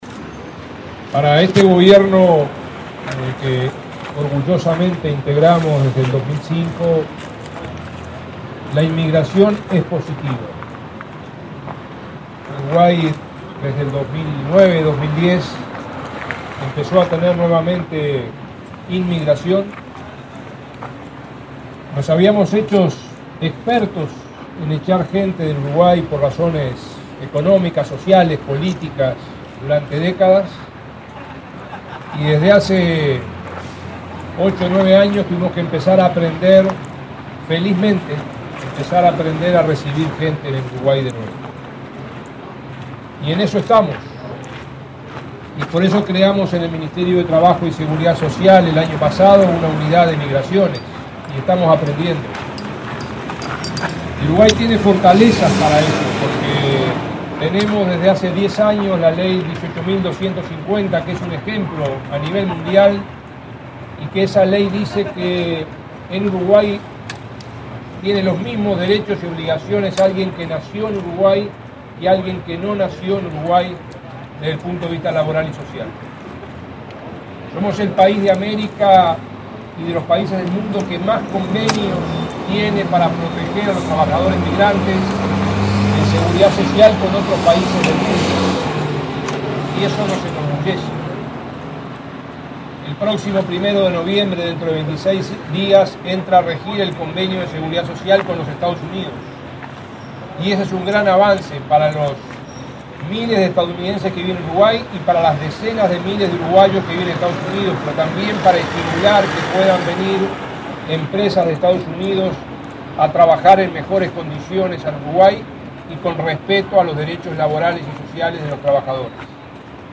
El ministro Ernesto Murro recordó, al inaugurar en su sede el mural “Gracias a vos” dedicado a la inmigración latinoamericana, que el 1.° de noviembre comienza a regir el convenio de seguridad social con Estados Unidos. Dijo que para el Gobierno la inmigración es positiva y que somos el país de América que más convenios tiene para proteger a trabajadores migrantes.